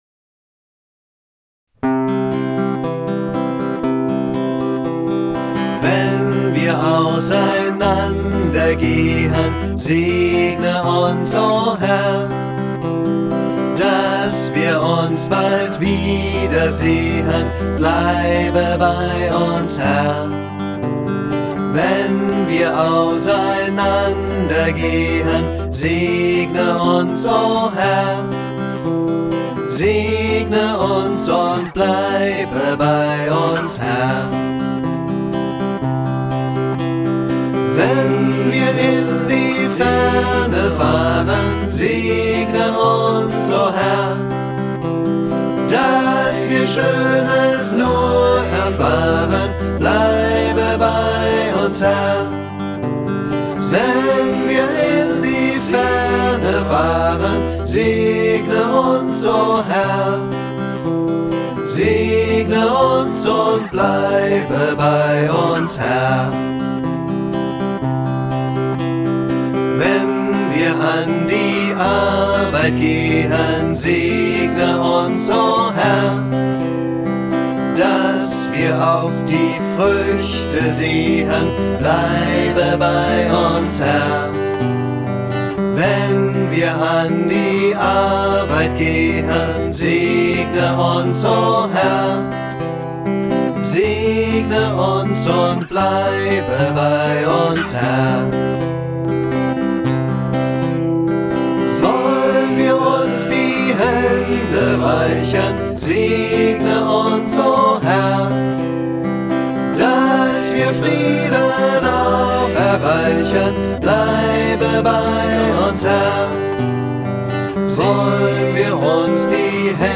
Ein neues Segenslied, welches sich auch gut als Kanon eignet